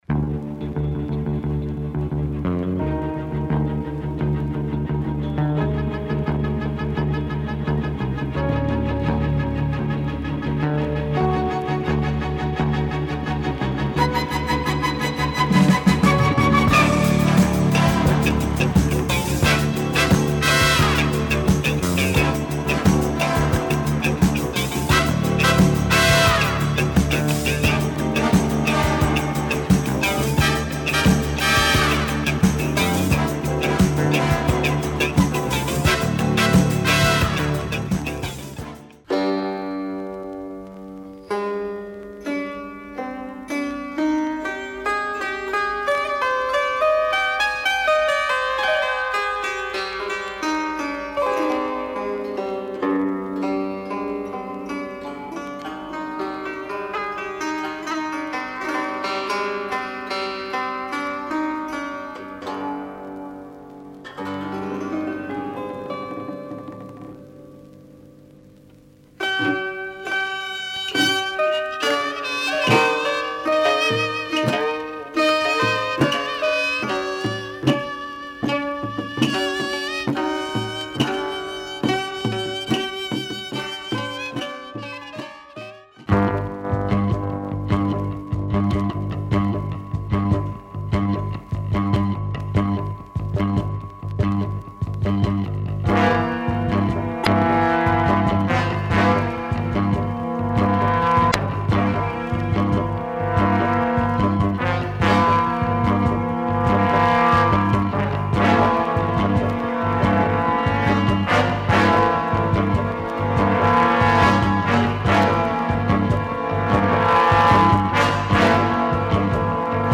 Three nices groovy cuts on this French soundtrack
The last one has a strong Asian vibe.